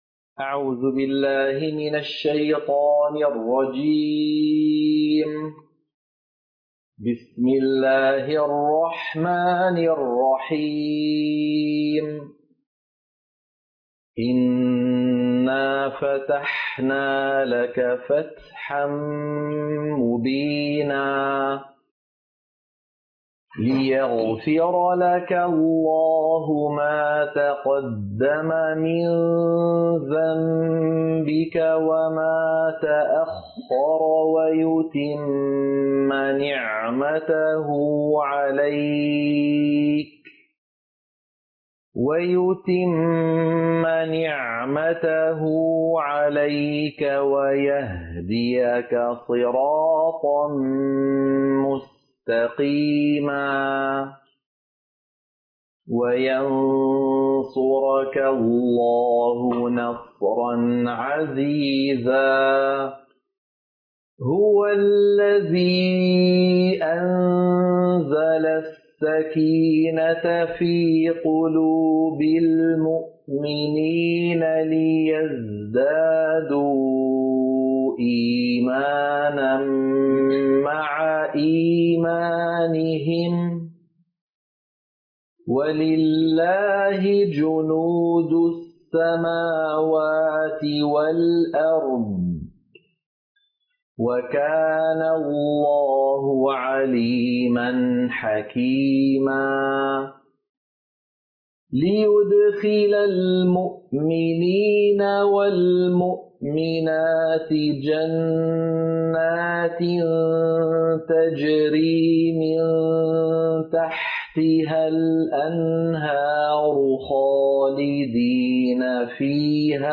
سورة الفتح - القراءة المنهجية